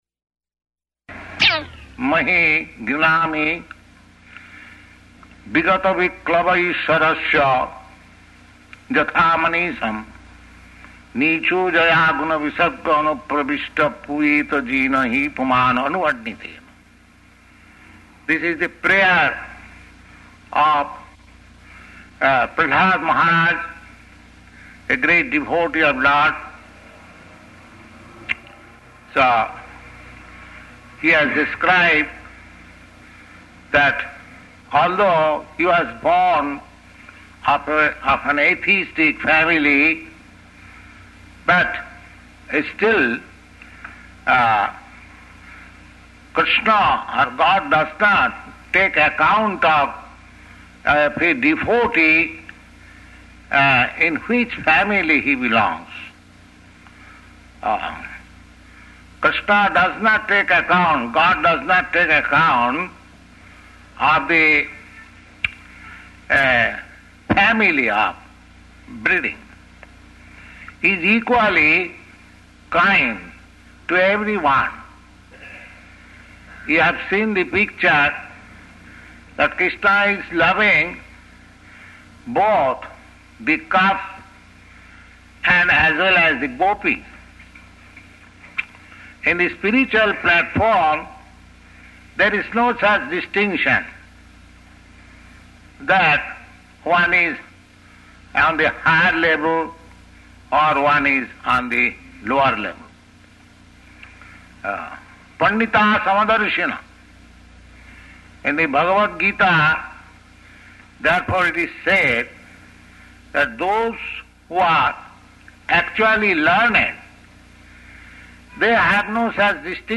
Location: Montreal